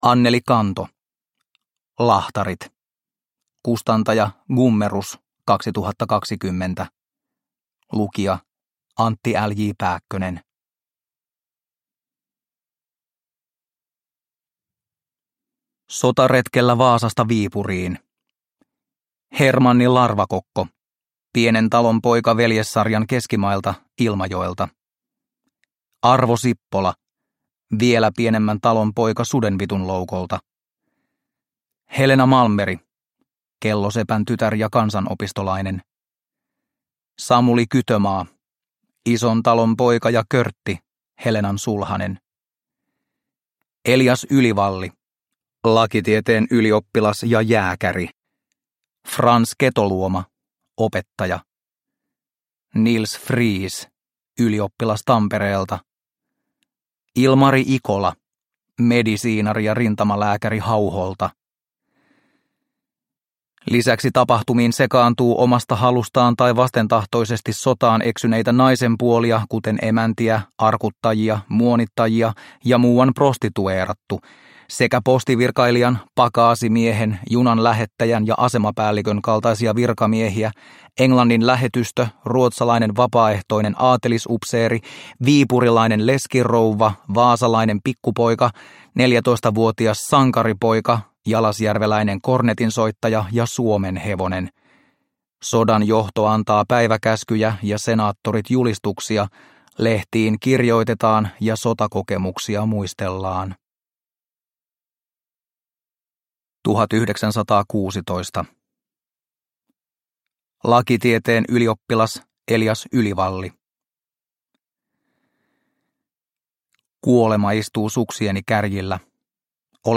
Lahtarit – Ljudbok – Laddas ner